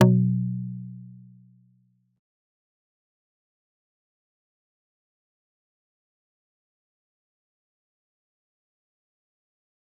G_Kalimba-B2-pp.wav